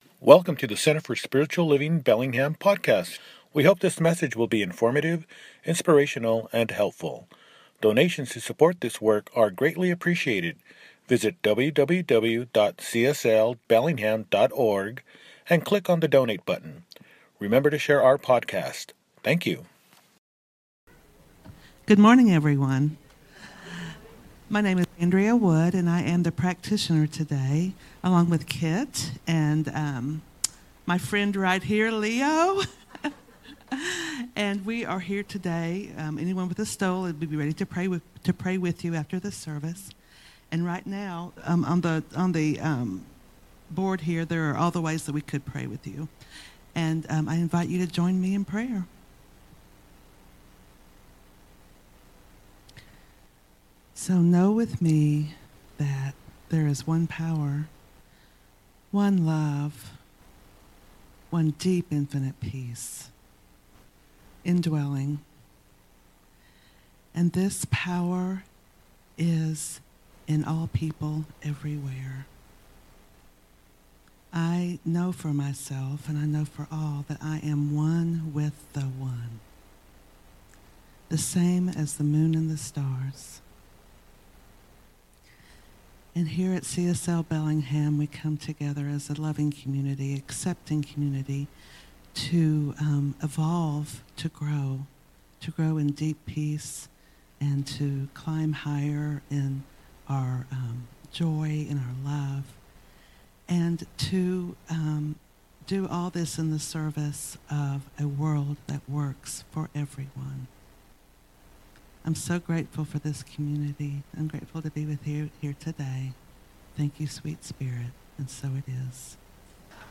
The Open Channel: All About The Great “I Am” – Celebration Service